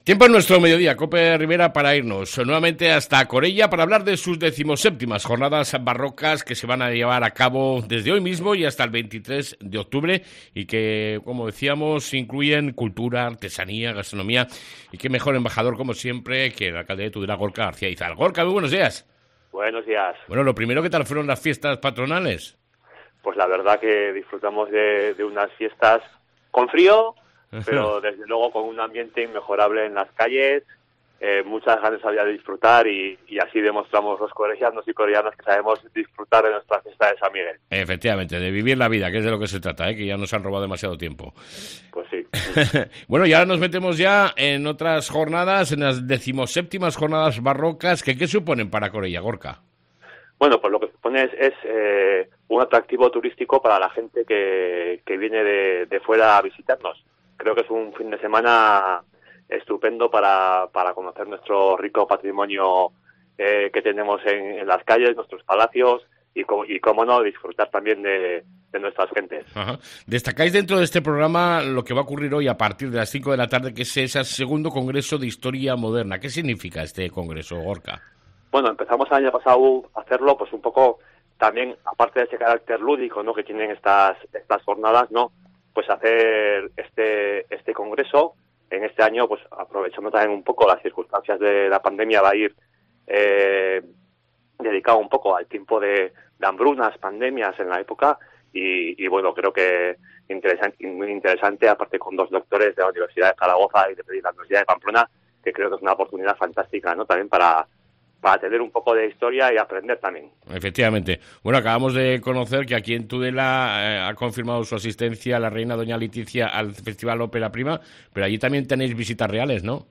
ENTREVISTA CON EL ALCALDE DE CORELLA , GORKA GARCÍA IZAL